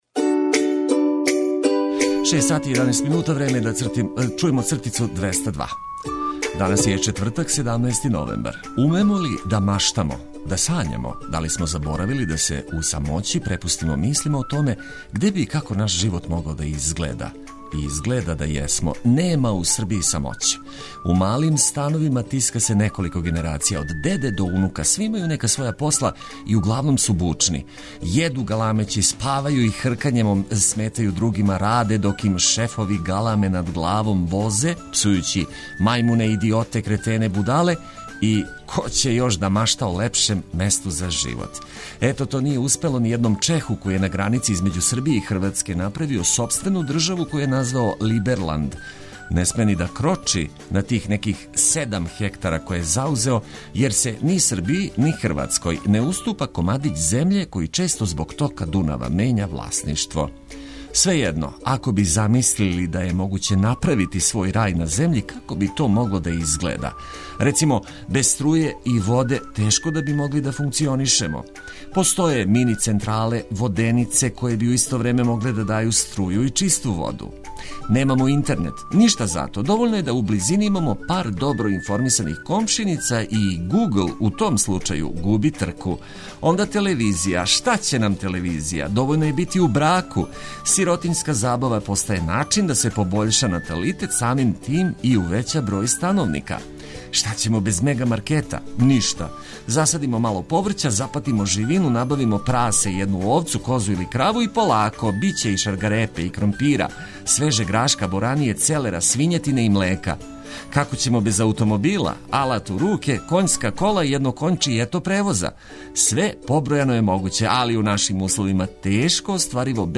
Ово јутро разведриће лепе и корисне приче зачињене музиком за разбуђивање.